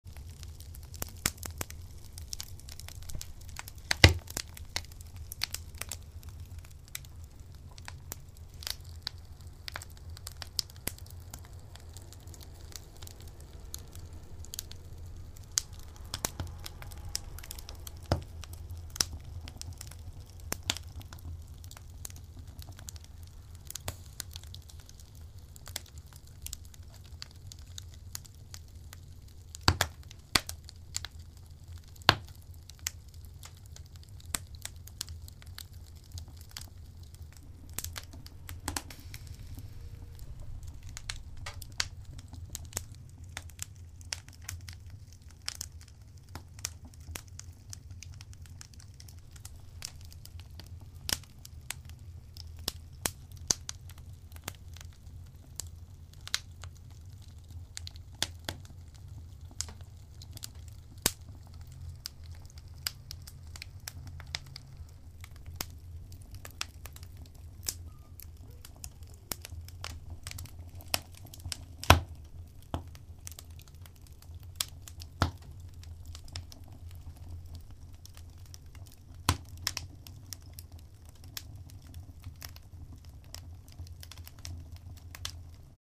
Треск дров (закрыта)